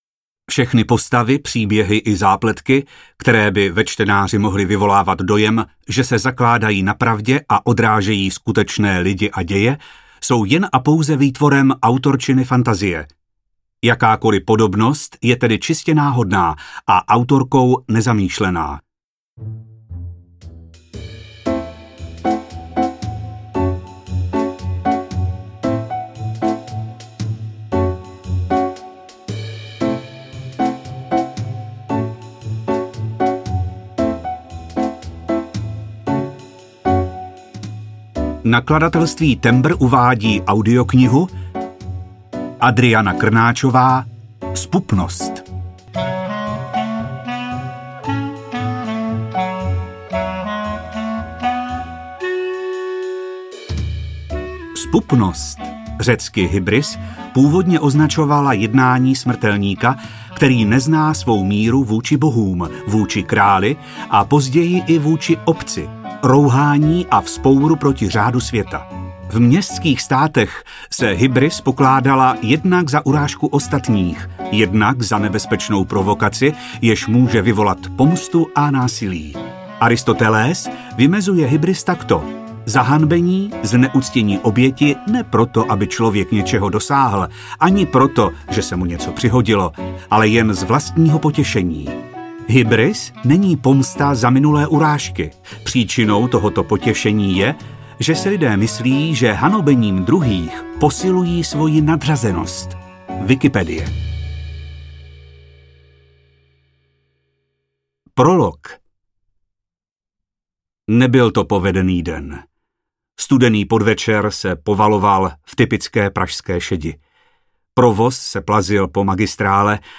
Zpupnost audiokniha
Ukázka z knihy